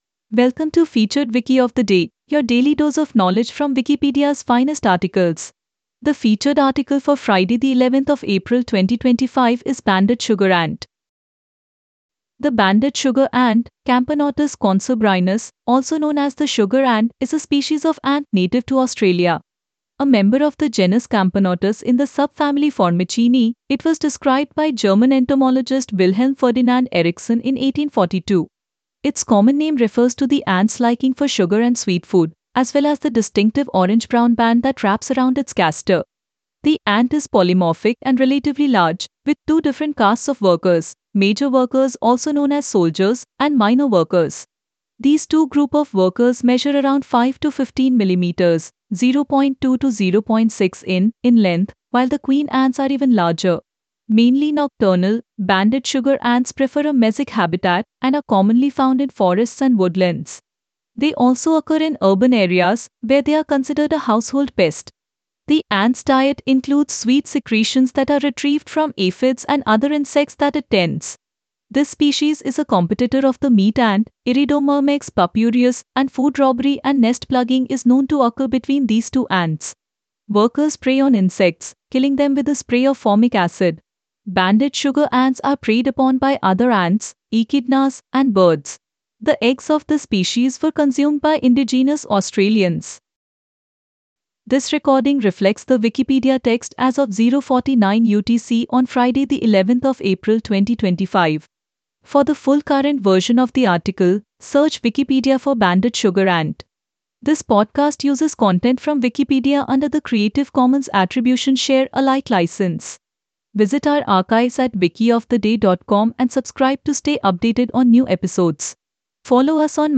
Until next time, I'm generative Kajal.